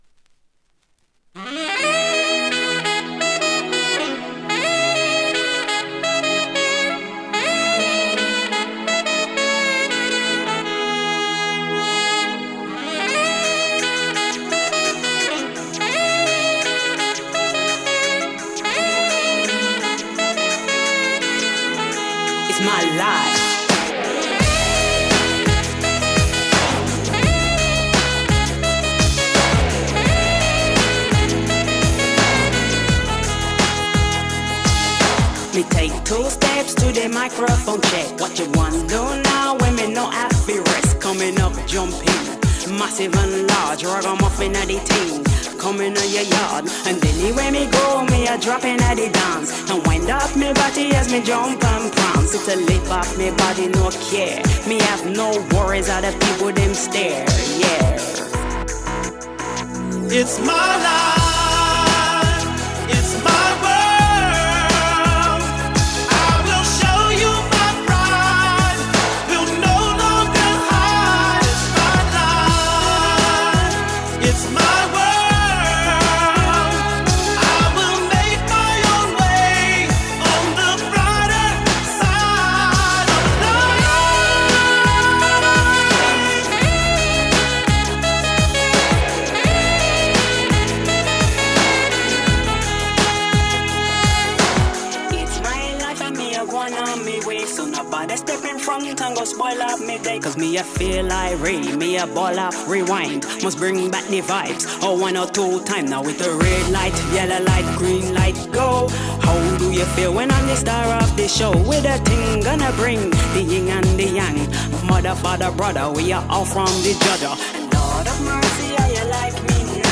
Жанр: Rap, Hip-Hop